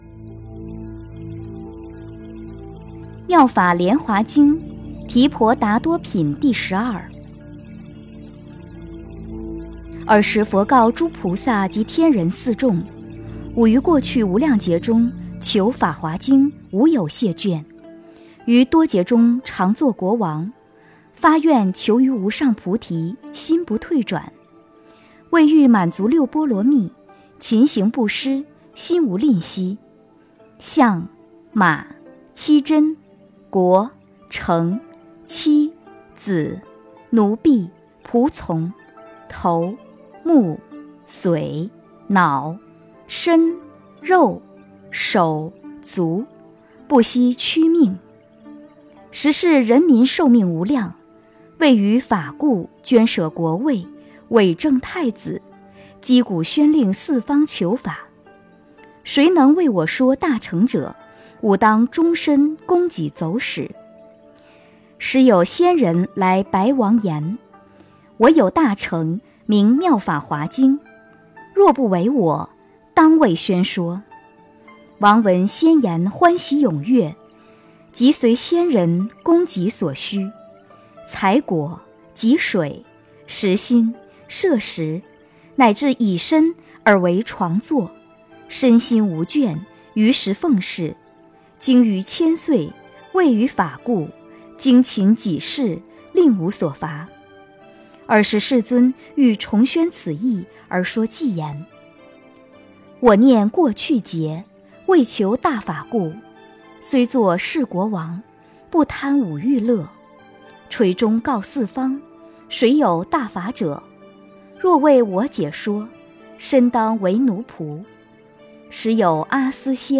《妙法莲华经》提婆达多品第十二 - 诵经 - 云佛论坛